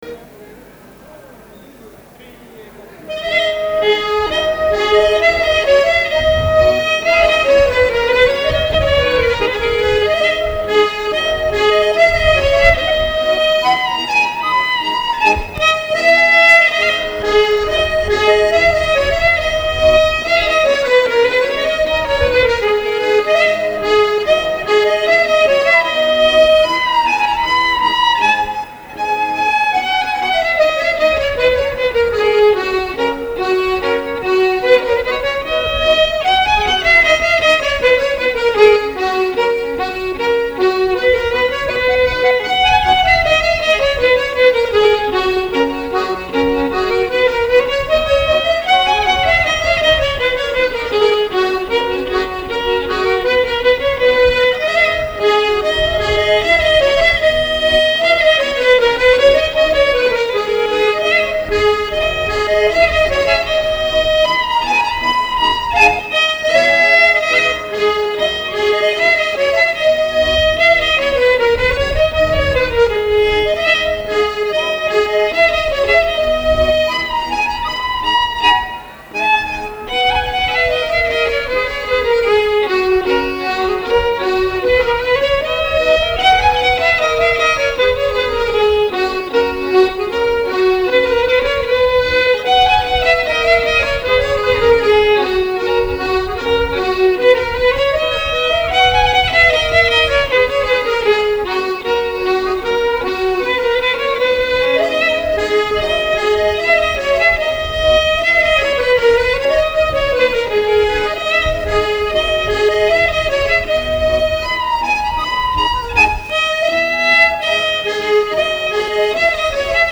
Pairis (scottish veneta)